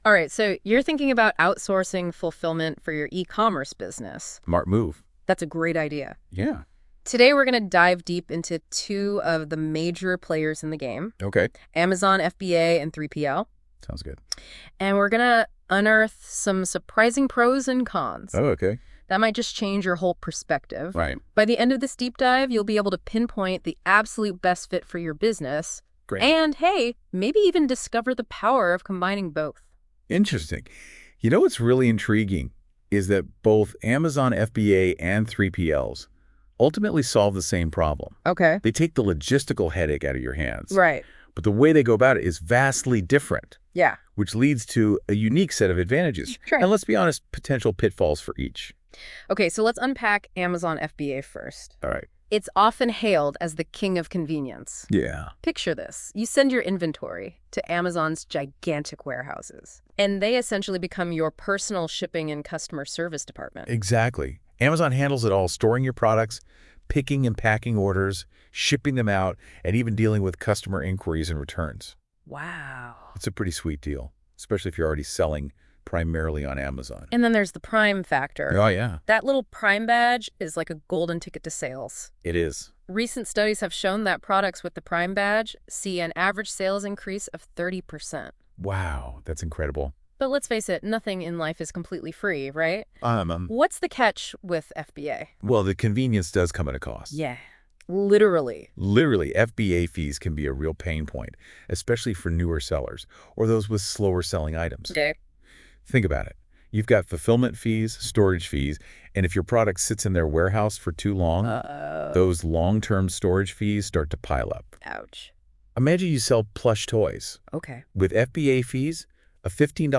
Amazon FBA Vs. 3PL by eFS & NotebookLM